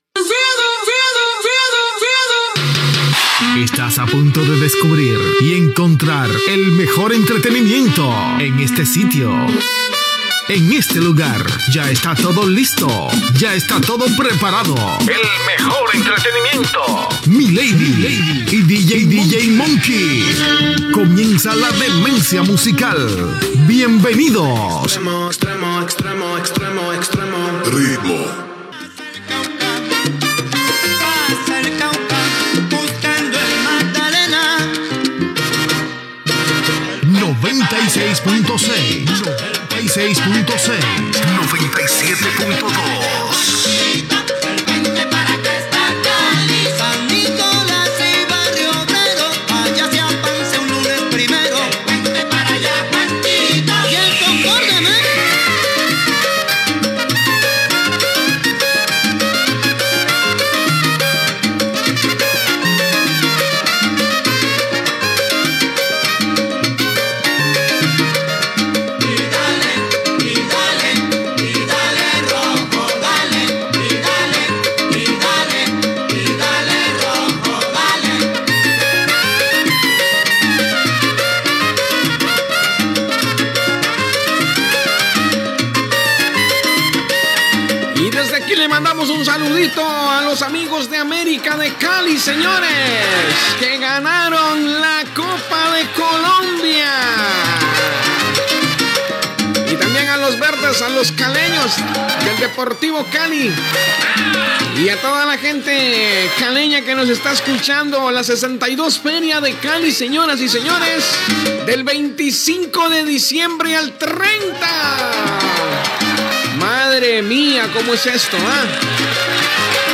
Llatina
Careta del programa, dues freqüències d'emissió, tema musical, salutació a la Fira de Cali, missatges de l'audiència, deu errades que maten la passió sexual
FM